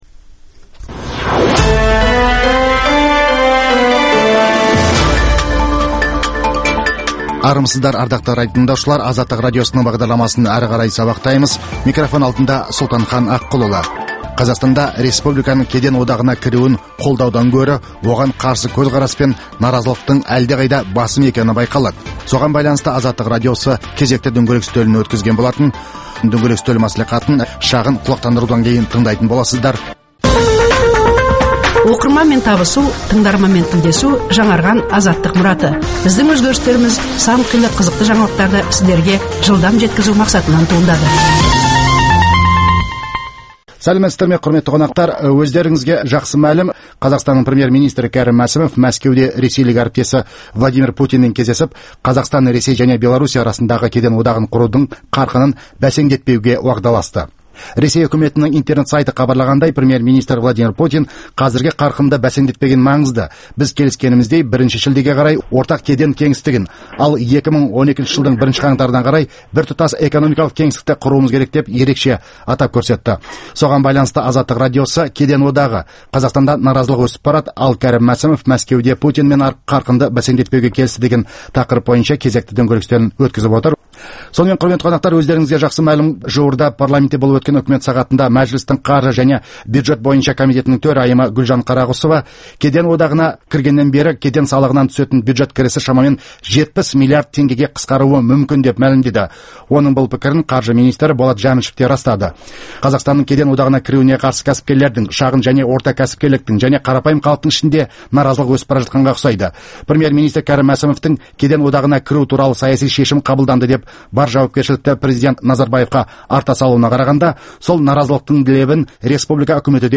Саяси және әлеуметтік саладағы күннің өзекті деген күйіп тұрған тақырыптарын қамту үшін саясаткерлермен, мамандармен, Қазақстаннан тыс жердегі сарапшылармен өткізілетін талқылау, талдау сұхбаты.